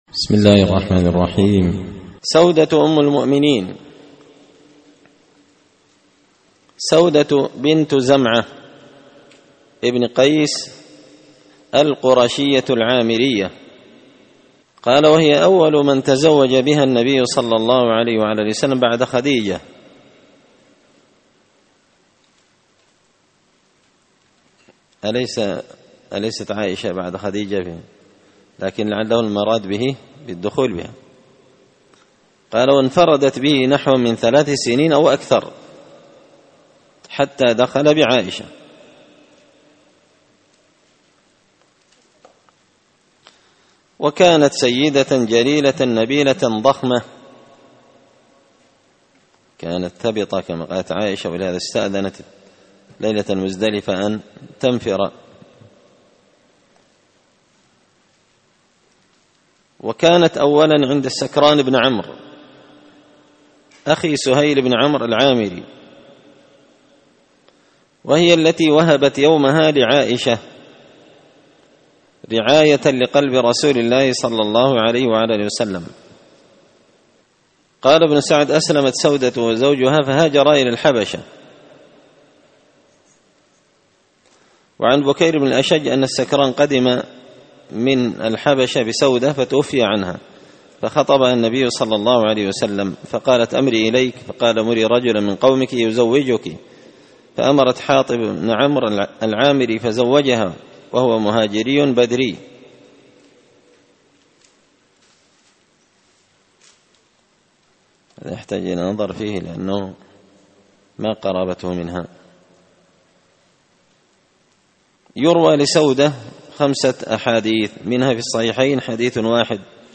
الدرس 89 أم المؤمنين سودة بنت زمعة
دار الحديث بمسجد الفرقان ـ قشن ـ المهرة ـ اليمن